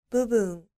• ぶぶん
• bubun